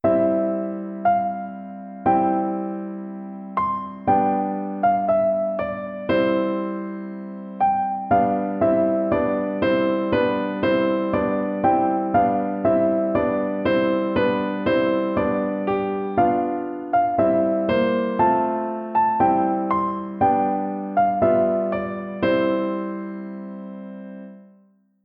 Crashkurs Klavier Online Nr. 6 → Das Kätzchen ist erwachsen: ABC, die Katze lief im Schnee - Musikschule »allégro«
KlavierkursNr063ABCDieKatzeLiefImSchneeFlott.mp3